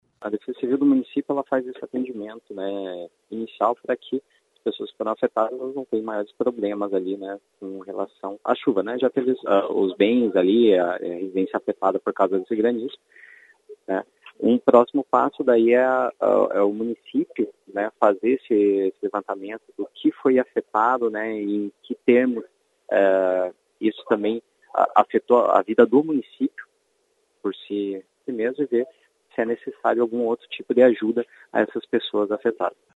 O tenente